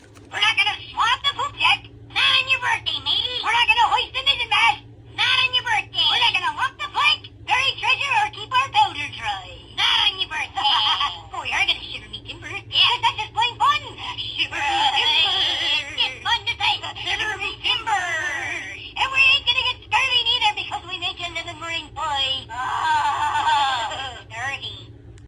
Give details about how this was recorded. Beware The Poop Deck! is a hoops&yoyo greeting card with sound made for birthdays.